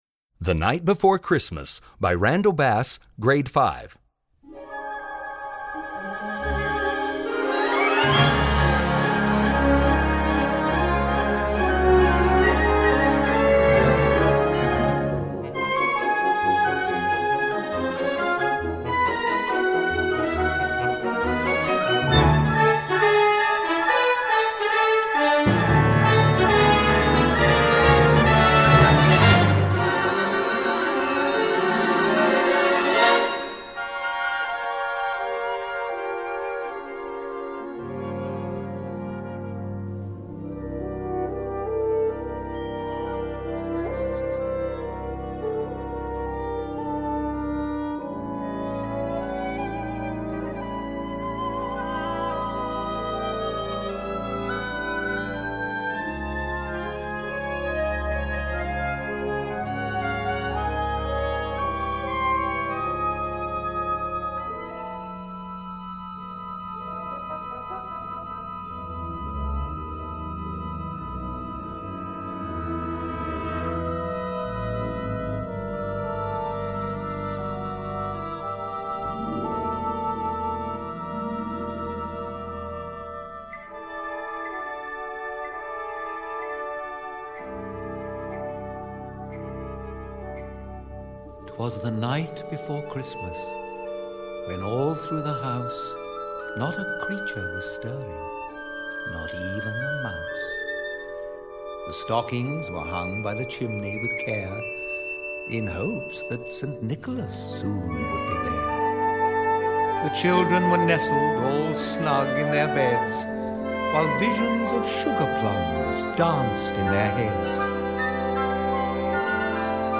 Gattung: Weihnachtliche Blasmusik
Besetzung: Blasorchester